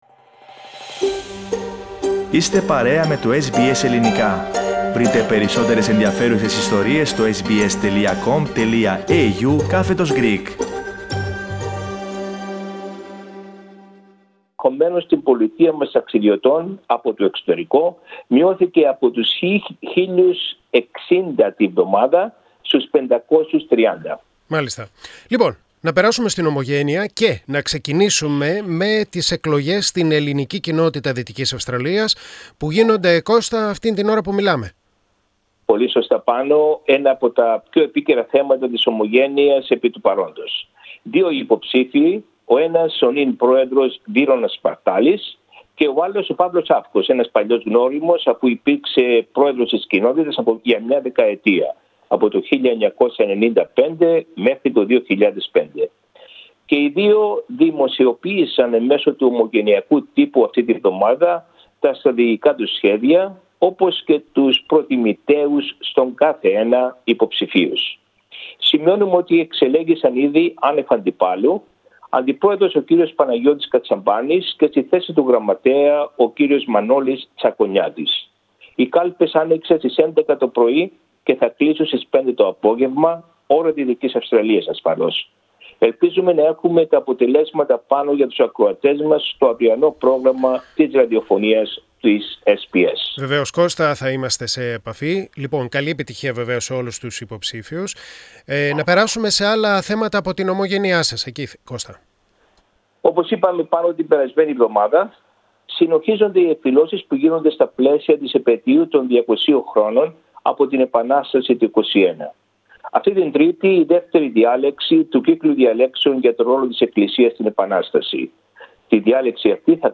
Τα νέα από την ομογένεια της Δυτικής Αυστραλίας στην εβδομαδιαία ανταπόκριση από την Πέρθη.